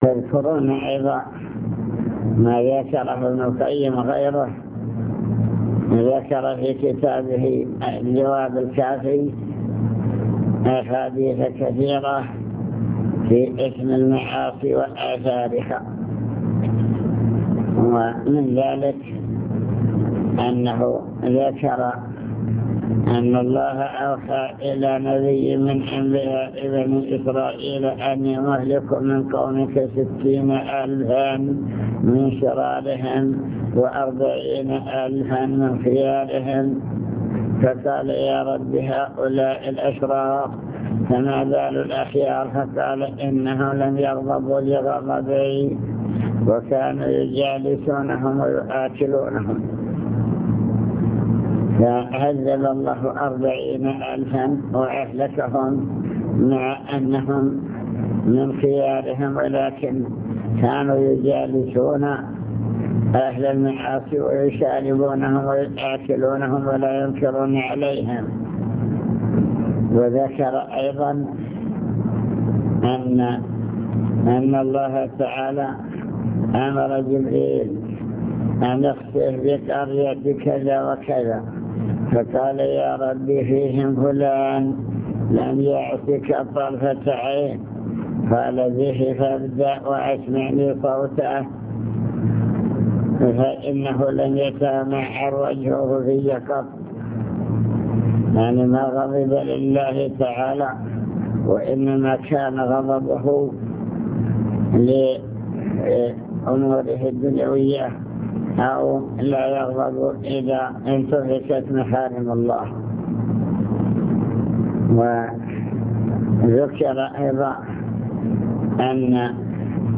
المكتبة الصوتية  تسجيلات - لقاءات  كلمة في رئاسة الأمر بالمعروف الدعوة إلى الله تعالى